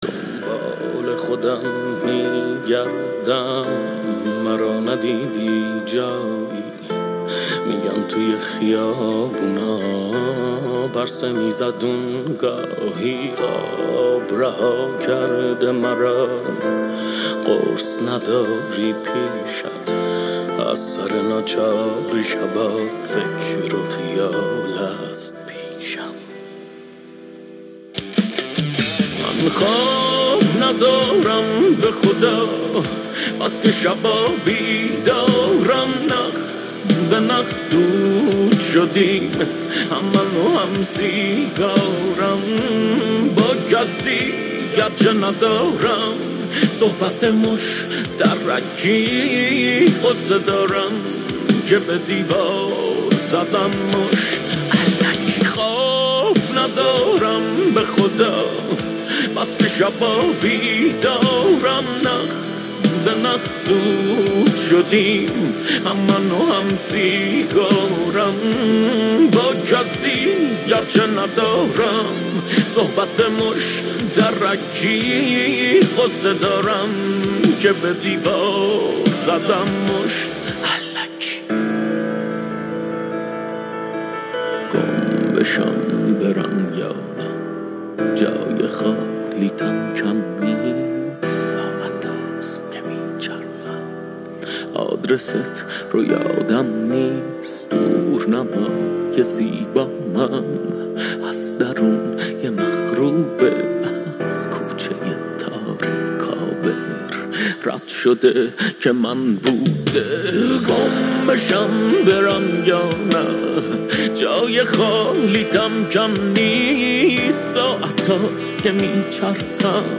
موسیقی سریال